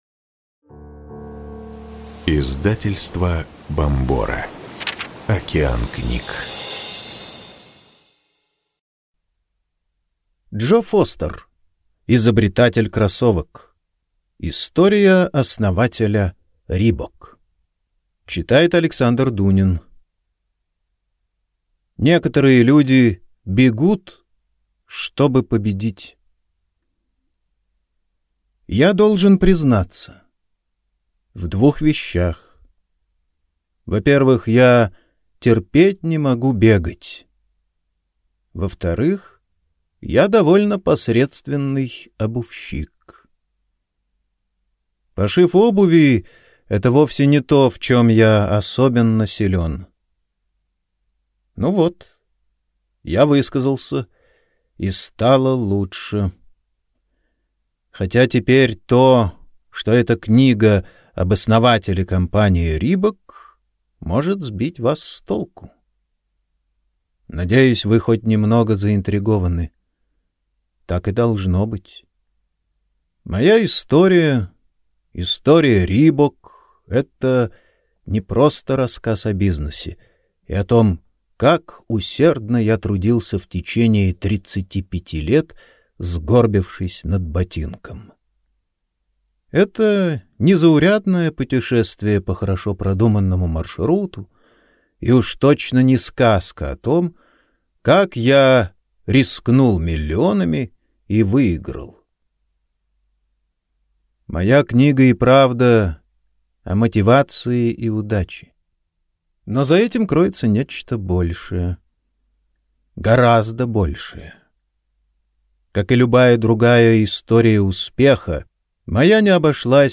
Аудиокнига Изобретатель кроссовок. История основателя Reebok | Библиотека аудиокниг